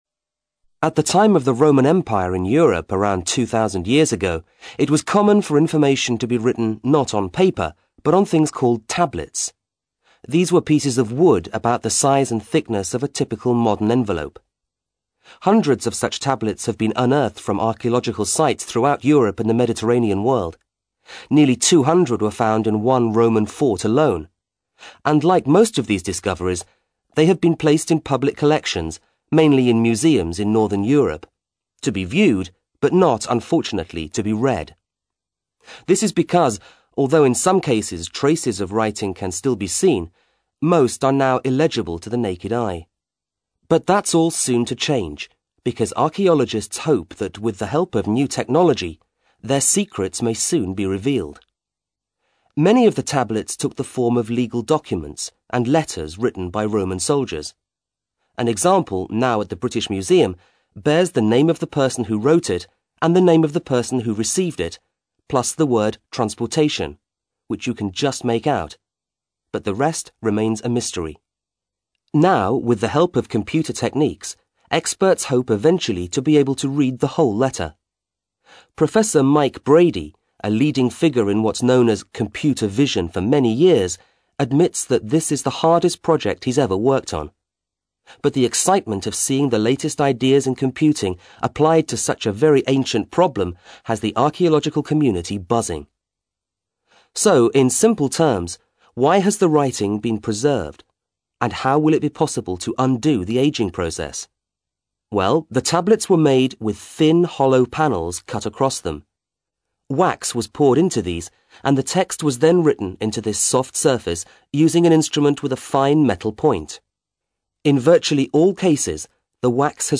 ACTIVITY 146: You will hear a short radio report about how technology is helping archaelogists who want to learn more about some texts written over 2,000 years ago known as Roman tablets.